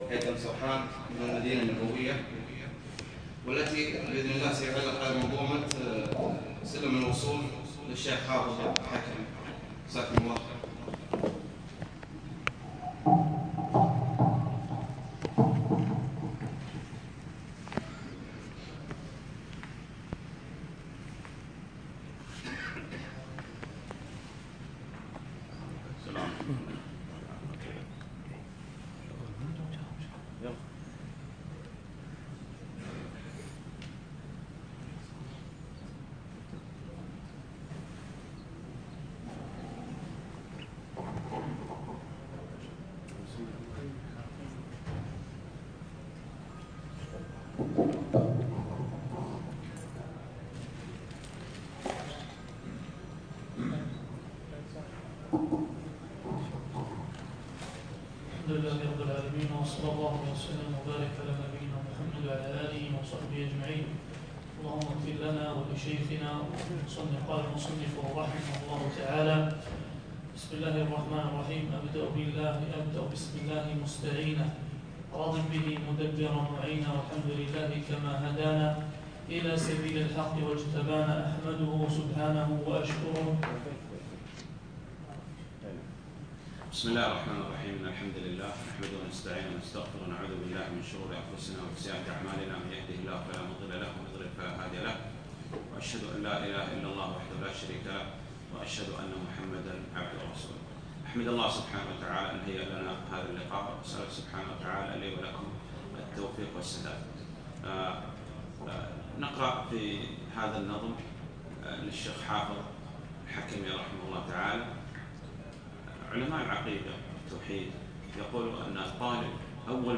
شرح منظومة سلم الوصول للحافظ الحكمي (في كلية الشريعة)